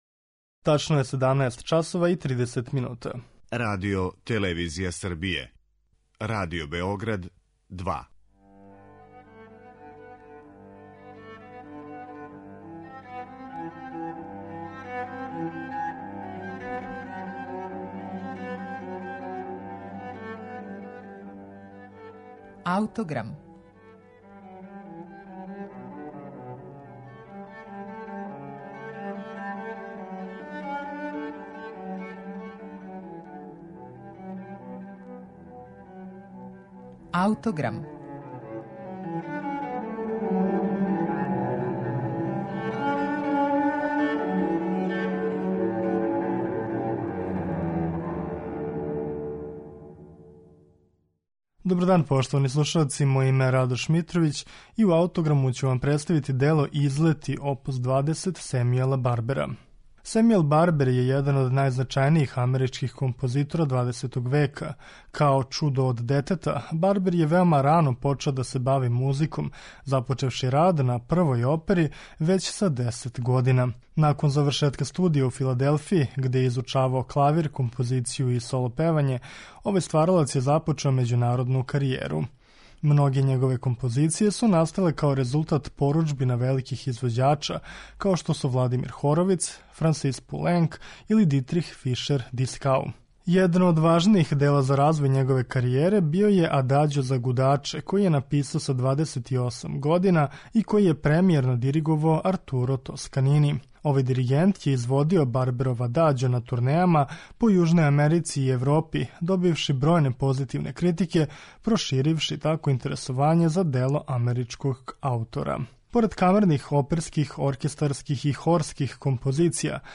за клавир
клавирско остварење